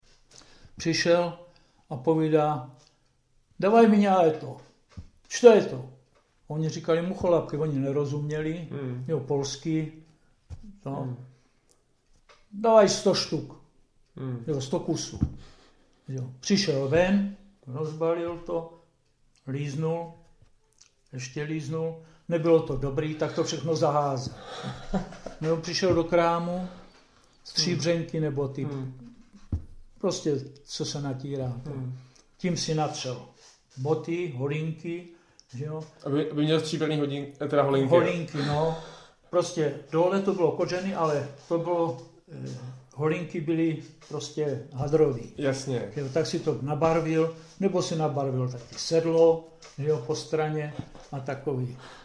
Klip "Ruský voják a mucholapka" z vyprávění pamětníka